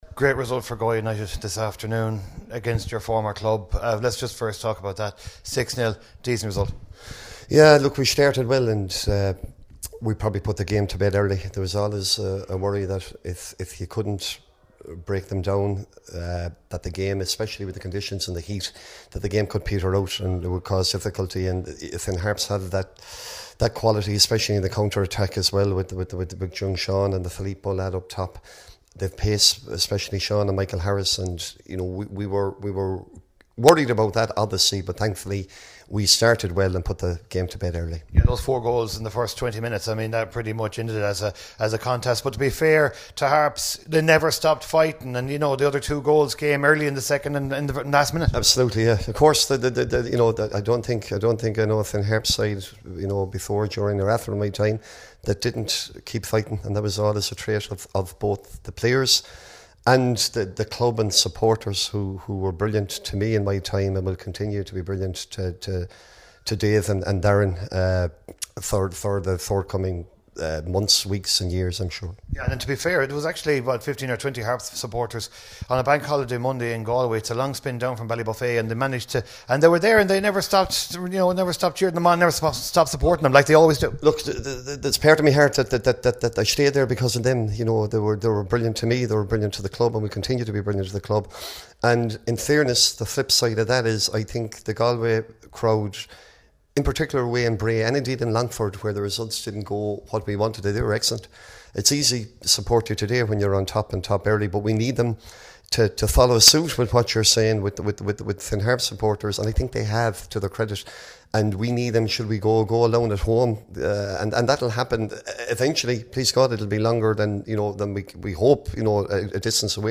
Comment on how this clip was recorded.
after Galway beat Harps 6-0 at Eamon Deacy Park